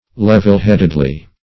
[WordNet 1.5] -- lev"el*head"ed*ly , adv.